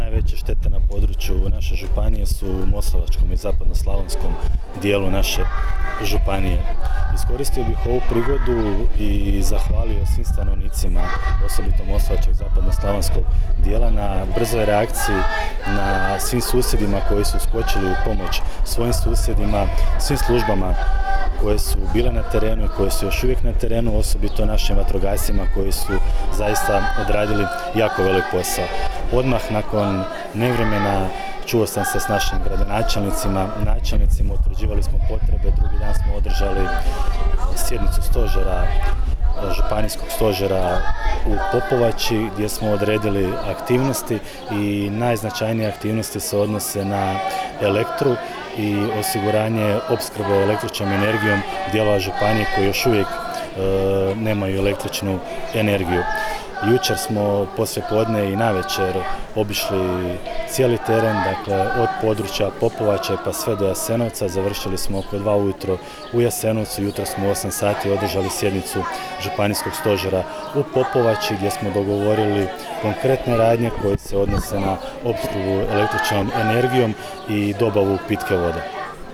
Najveći problem na terenu, uz oštećene stambene, gospodarske i objekte javne namjene predstavlja nedostatak el.energije, internetske i mobilne mreže što otežava komunikaciju i radove na sanaciji šteta, a također predstavlja i potencijalnu smetnju u opskrbi domaćinstava vodom, rekao je u izjavi za medije , danas u Sunji, gdje je nazočio obilježavanju Dana Općine Sunja, župan Ivan Celjak